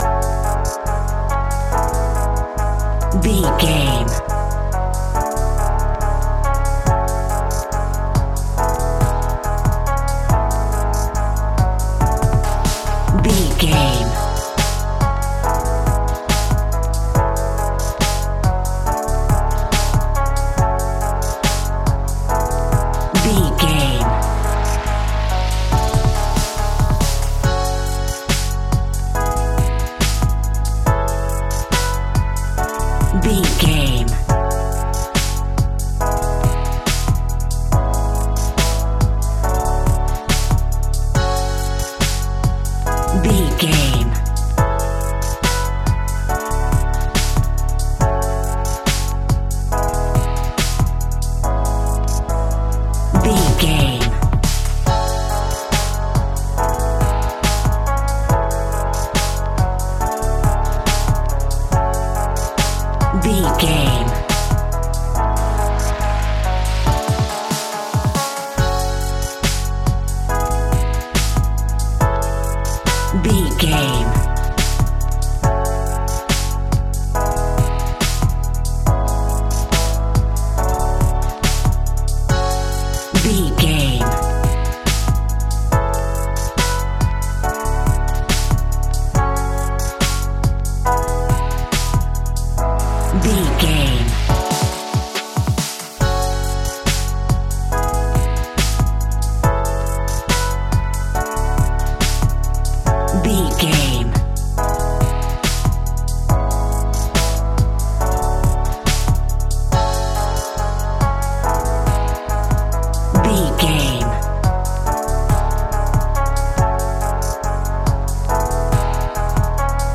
Aeolian/Minor
F♯
Slow
dreamy
melancholic
ethereal